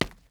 Sounds / sfx / Footsteps / Concrete / Concrete-07.wav
Concrete-07.wav